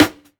004_Snare - Brooklyn Feels.wav